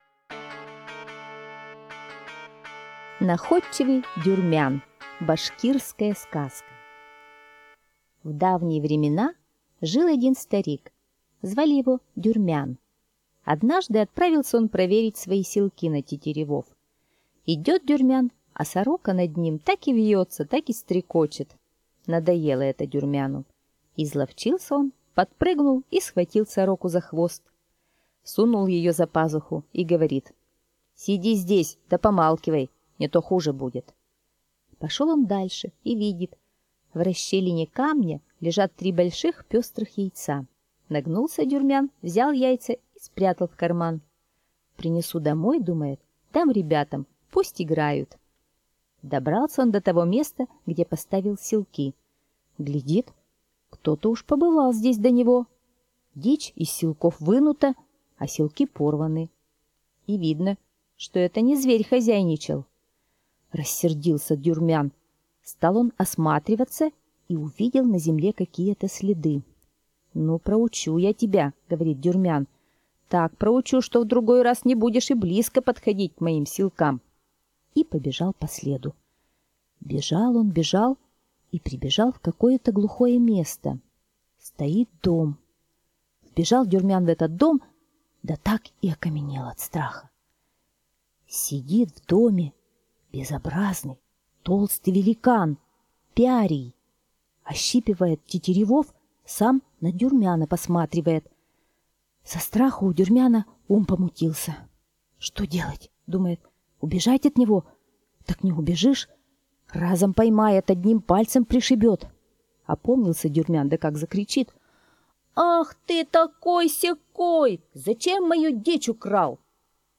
Находчивый Дюрмян - башкирская аудиосказка - слушать онлайн